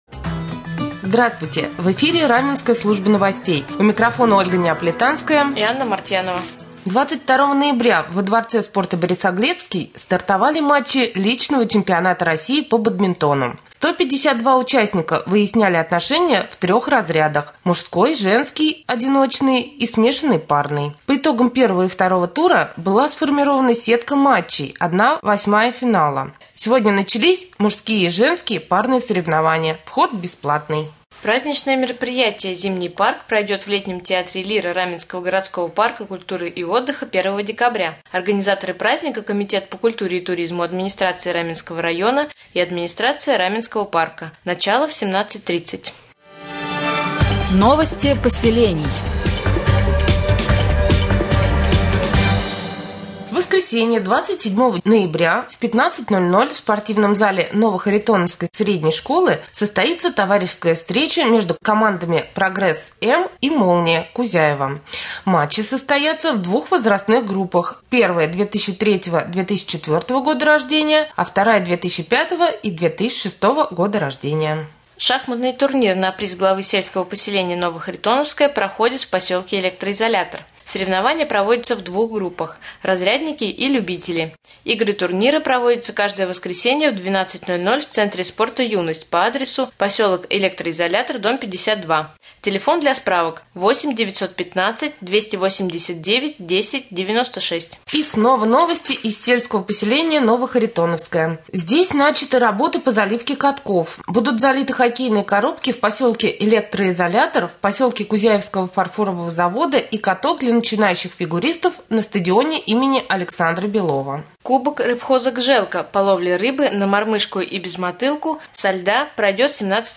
1. Новости